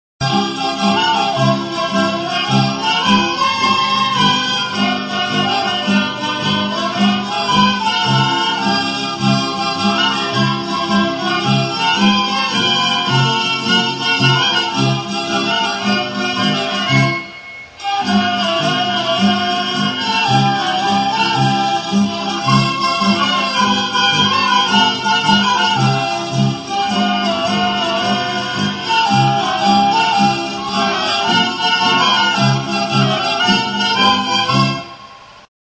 CLARINETE; KLARINETEA | Soinuenea Herri Musikaren Txokoa
Aerophones -> Reeds -> Single fixed (clarinet)
ARATUSTE ALAI. Lekeitioko Estudiantina; Lekitxoko Estudiantina. IZ 185 D; 1983.
KLARINETE